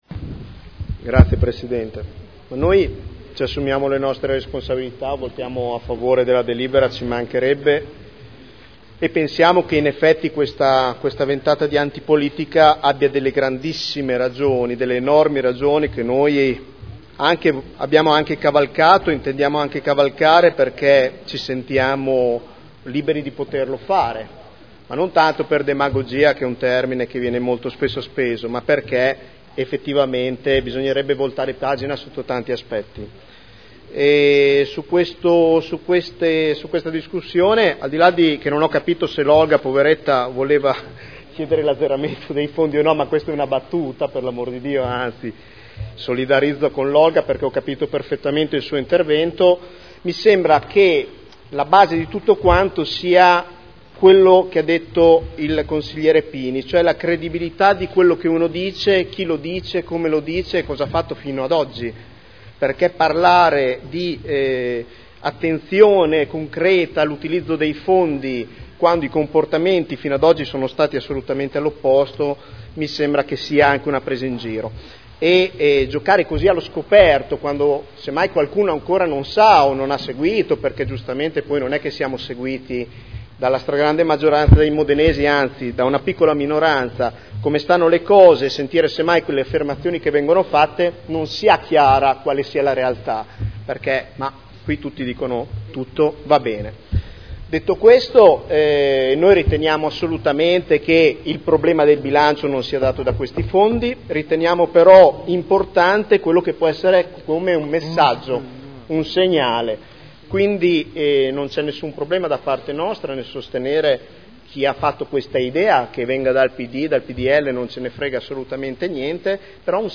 Seduta del 2 aprile. Proposta di deliberazione: Assegnazione risorse finanziarie ai gruppi consiliari – Anno 2012 – Anticipazione.